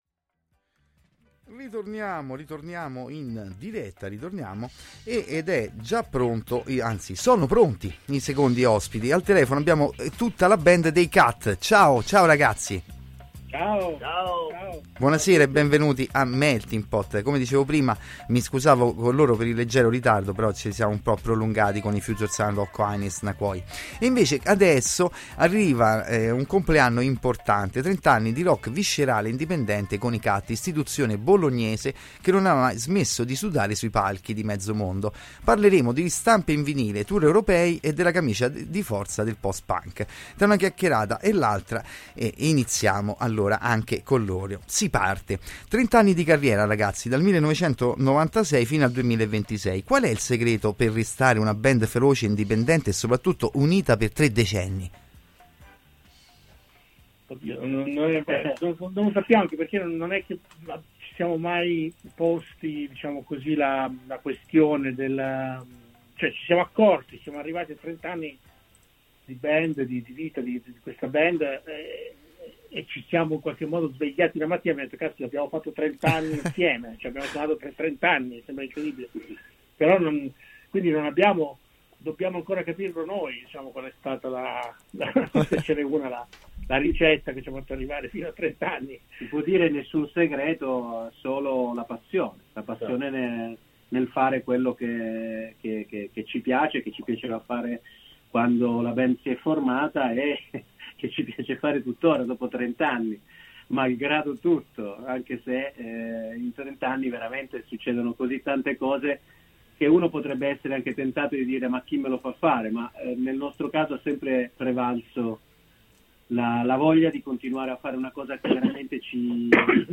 Intervista-CUT.mp3